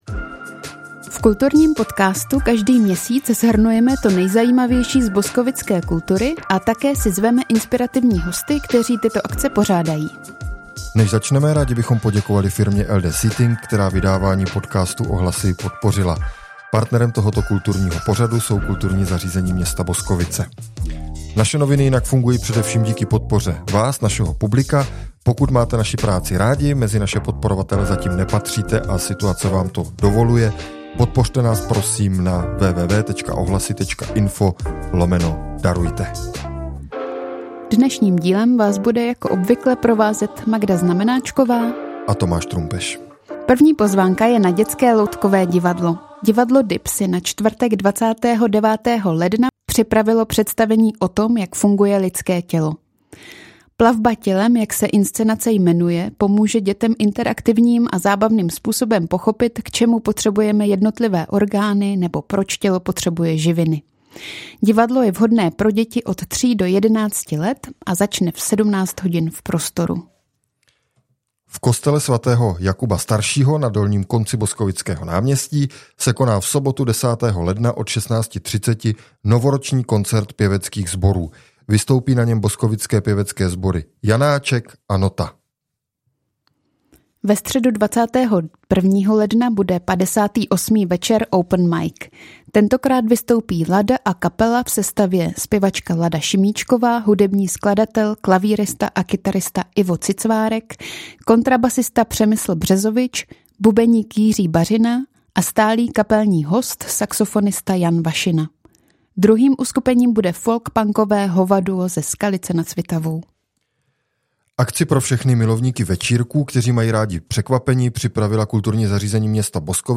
V kulturním podcastu každý měsíc shrnujeme to nejzajímavější z boskovické kultury a také si zveme inspirativní hosty, kteří tyto akce pořádají. K rozhovoru